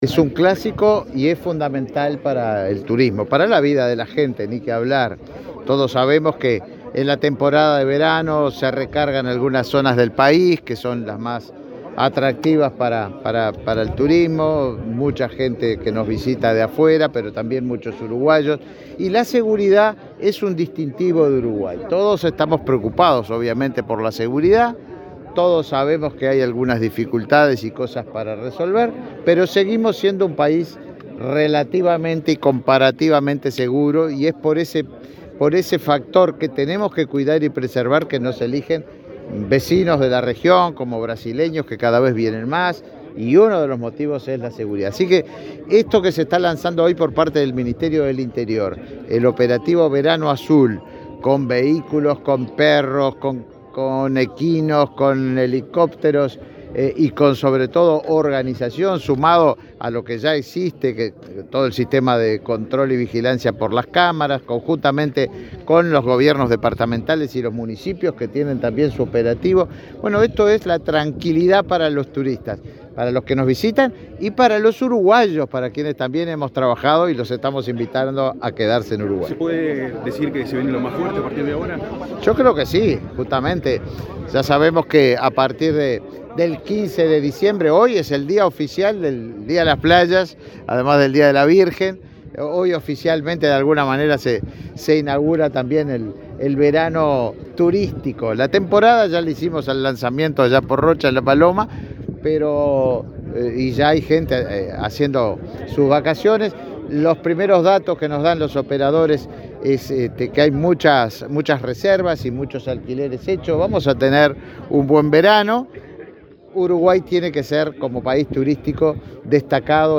Declaraciones del ministro de Turismo, Tabaré Viera
El ministro de Turismo, Tabaré Viera, dialogó con la prensa, este viernes 8 en el balneario Solís, Maldonado, luego de participar en el lanzamiento